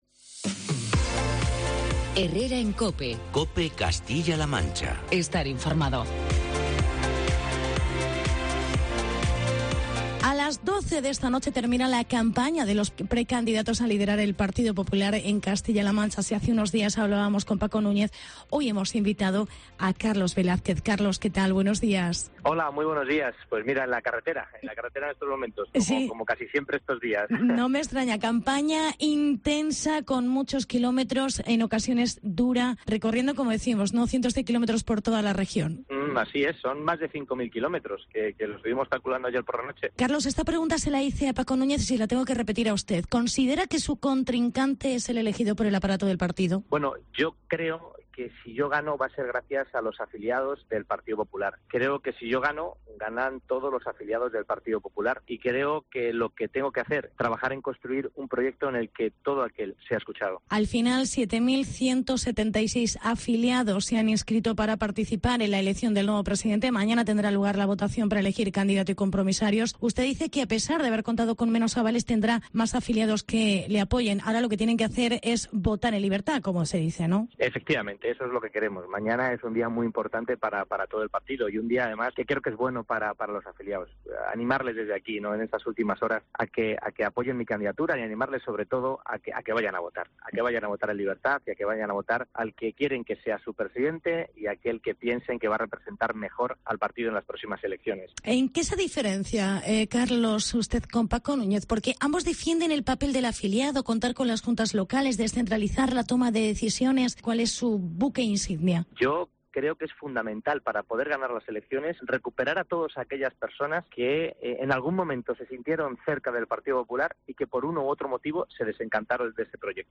Entrevista con Carlos Velázquez. Precandidato PP CLM